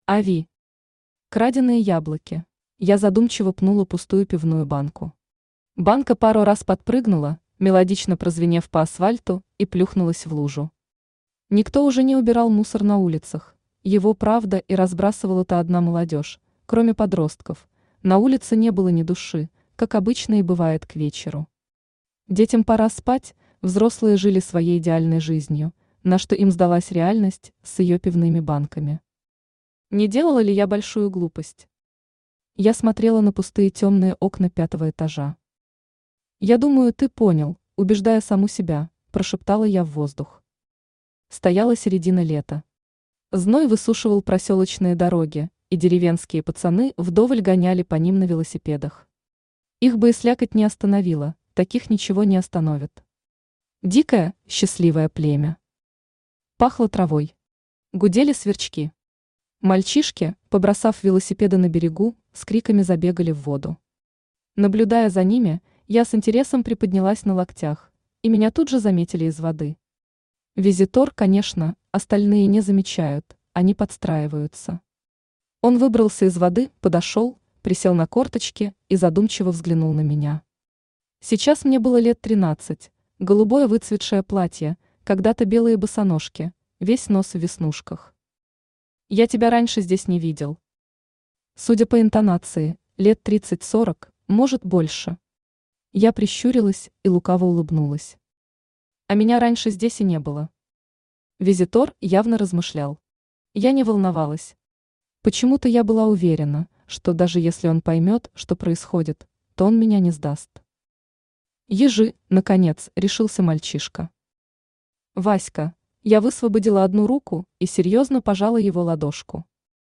Аудиокнига Краденые яблоки | Библиотека аудиокниг
Aудиокнига Краденые яблоки Автор Ави Читает аудиокнигу Авточтец ЛитРес.